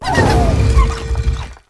tinkaton_ambient.ogg